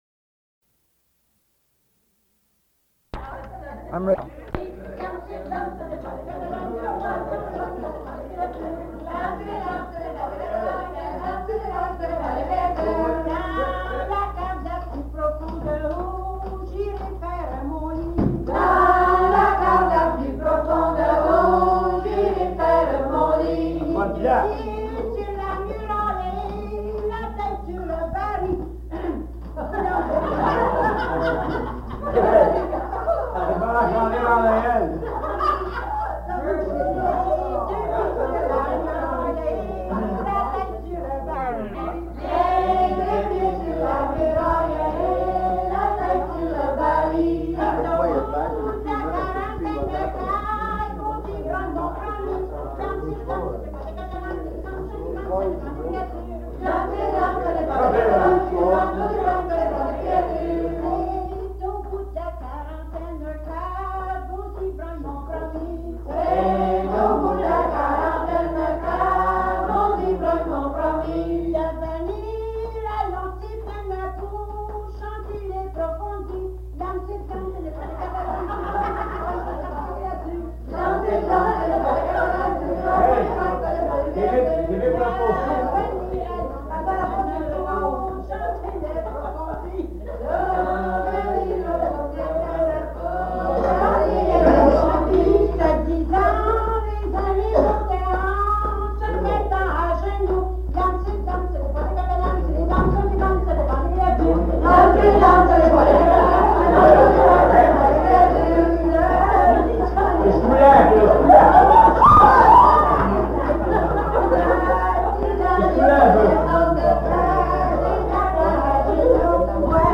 Folk Songs, French--New England
Franco-Americans--Music
Burlington (inhabited place)